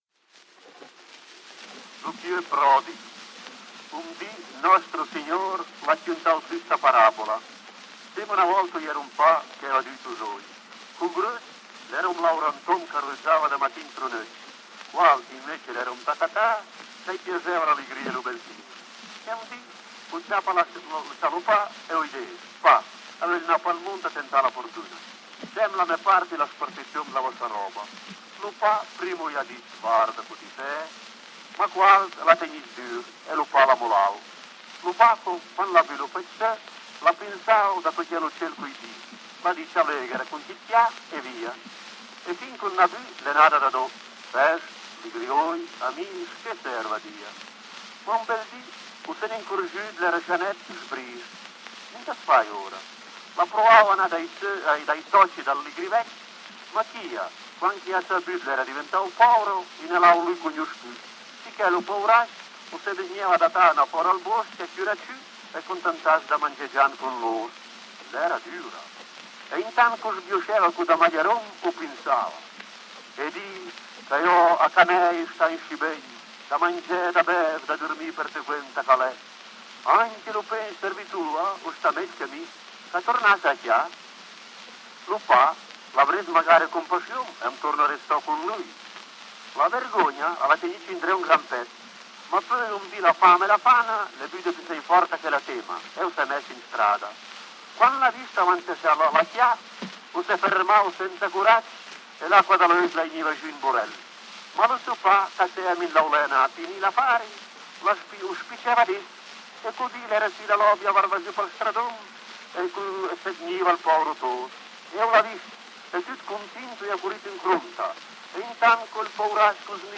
カヴェルニョ方言、１９１３年録音
イタリア語圏のカヴァルニョ方言で読む『ルカの福音書』の「放蕩息子のたとえ話」。
Phonogram Archive, Zurich
カヴァルニョ（イタリア語）、１９１３年録音